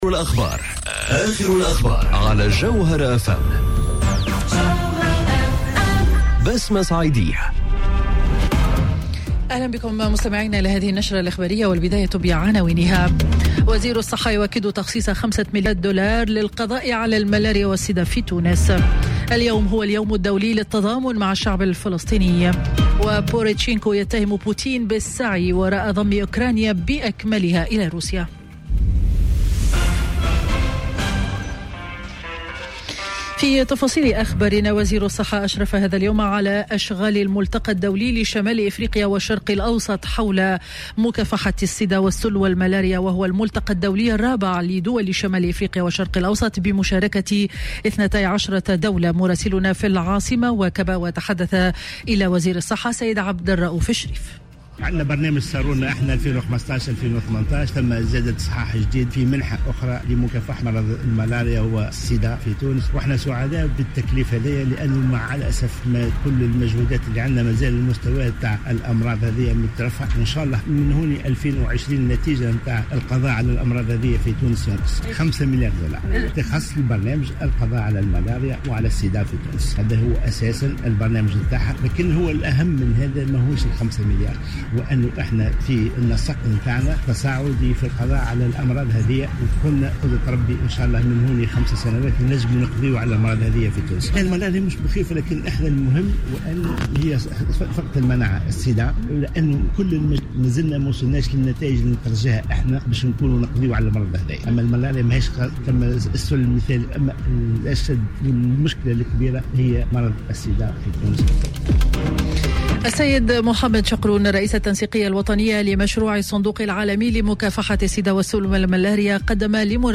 نشرة أخبار منتصف النهار ليوم الخميس 29 نوفمبر 2018